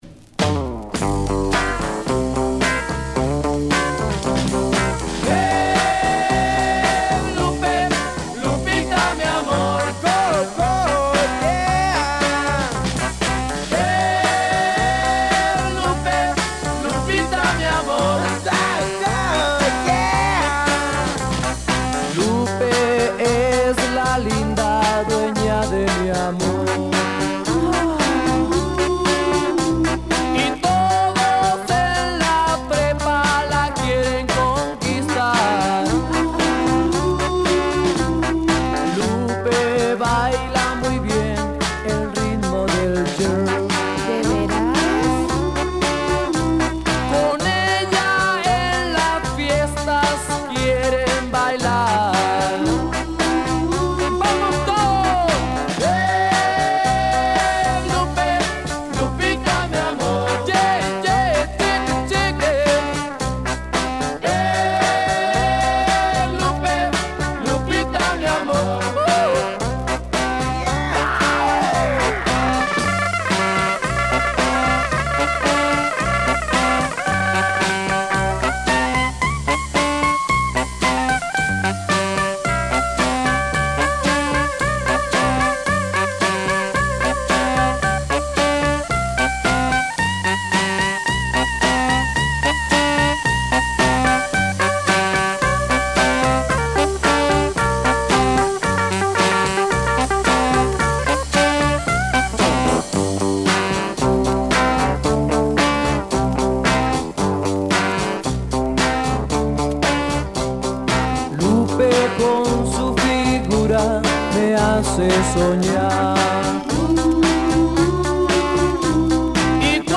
Funk/Soul International Rock